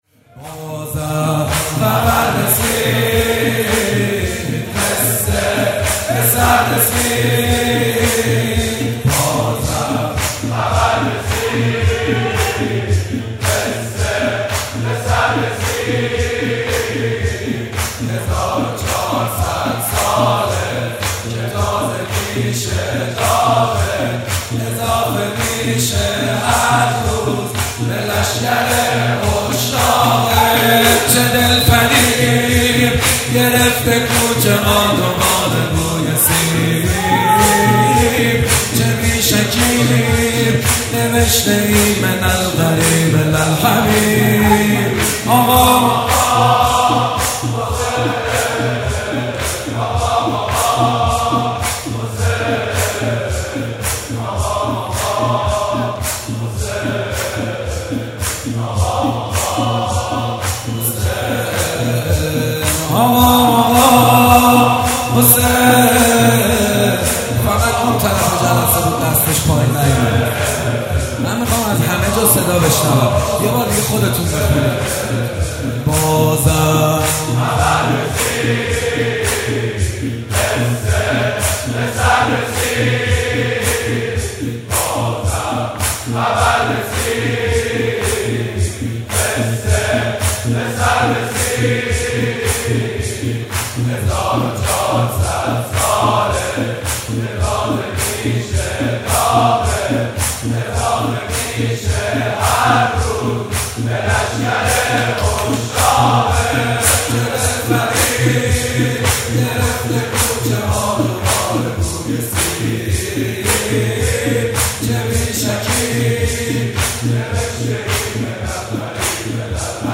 زمینه اول